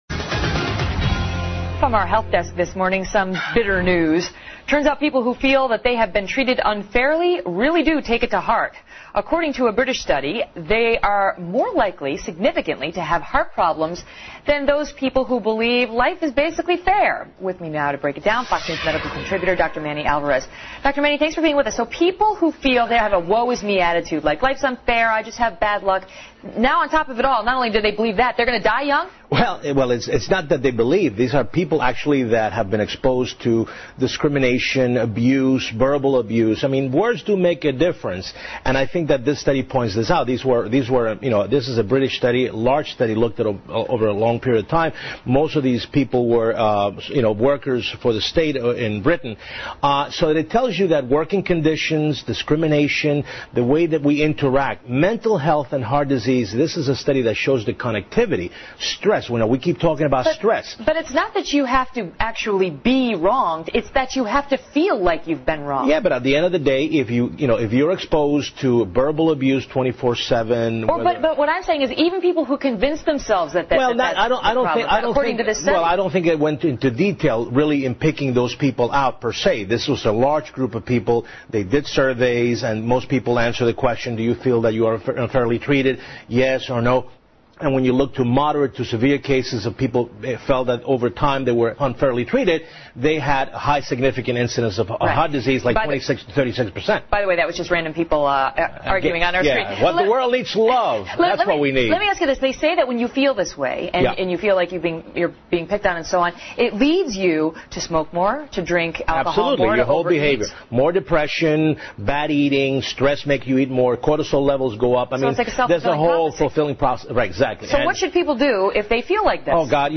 访谈录 Interview 2007-06-02&06-04, 不要事事放在心上 听力文件下载—在线英语听力室